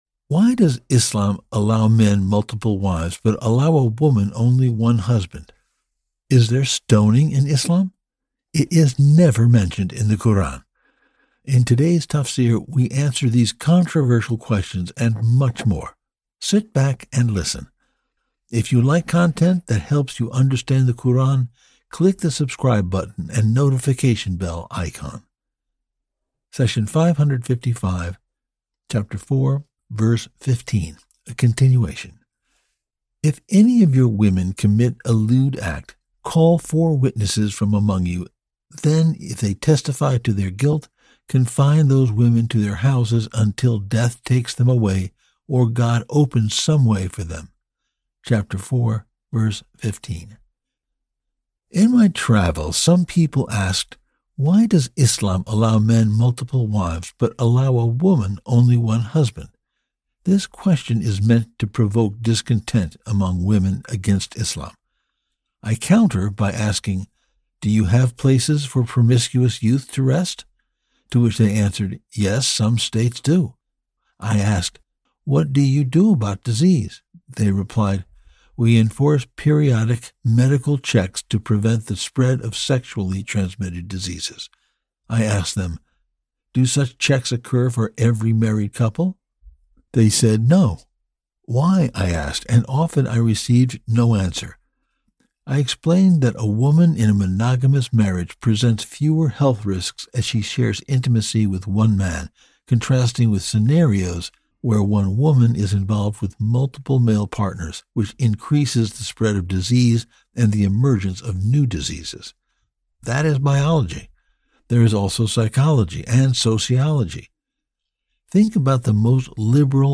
The Nobel Quran Explained in Clear English; professionally narrated and delivered to you weekly! Quran Garden is a word by word, verse by verse clear English Tafsir of the Quran.